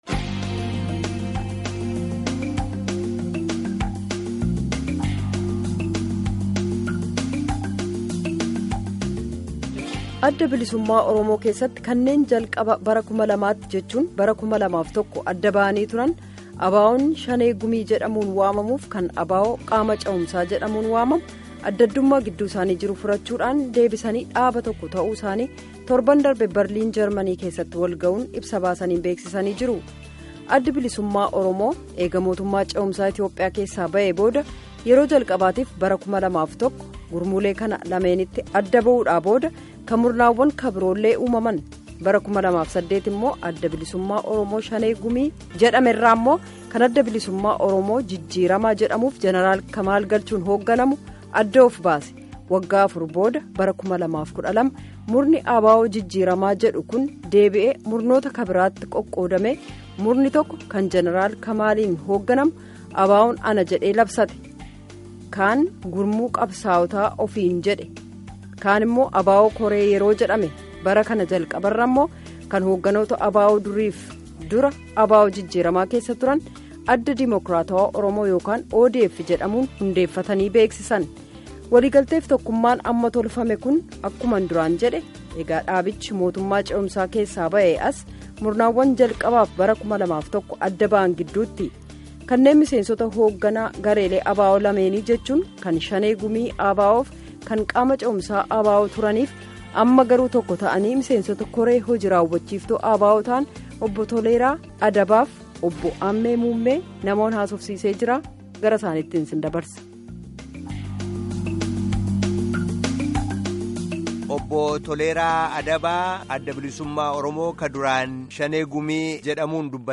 Embed share Gaaffii fi deebii guutu dhaggeeffadhaa by Sagalee Ameerikaa Embed share The code has been copied to your clipboard.